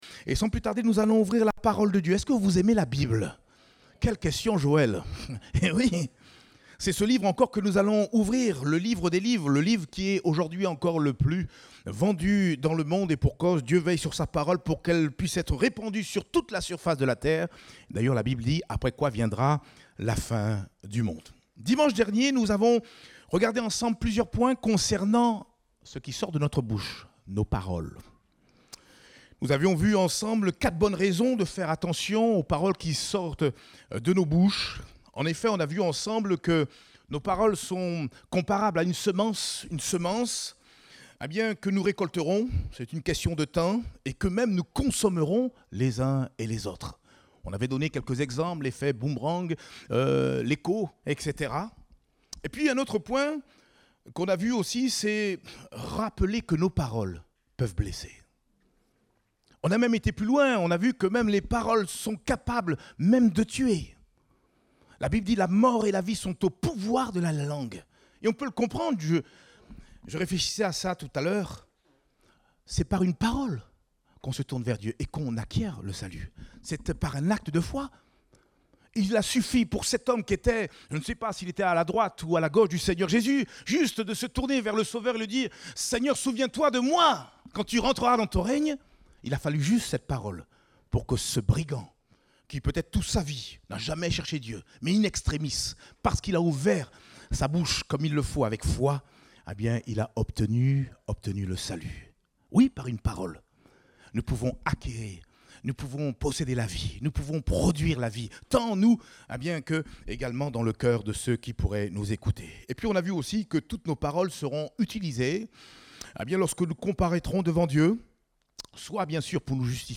Date : 11 septembre 2022 (Culte Dominical)